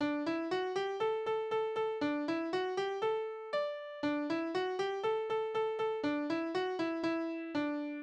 Tanzverse: Galopp
Tonart: D-Dur
Taktart: 4/8
Tonumfang: Oktave
Besetzung: vokal